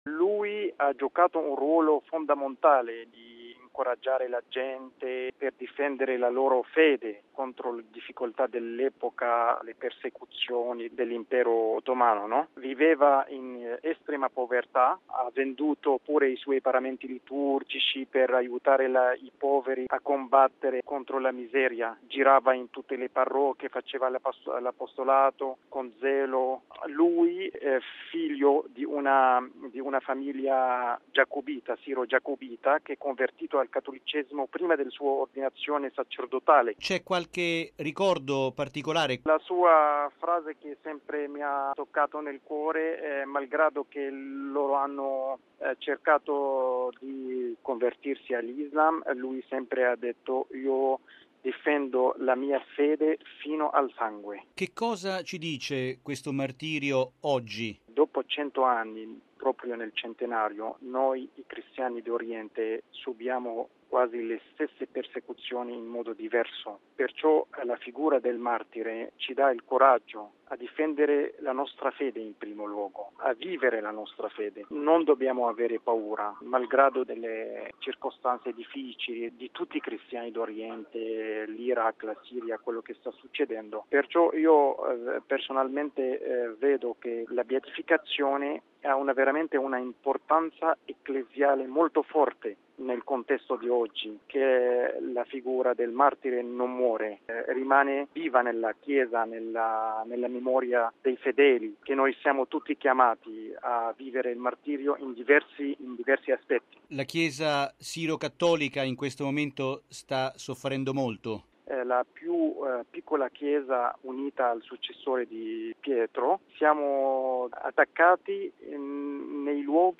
Sulla figura del prossimo Beato ascoltiamo il postulatore della Causa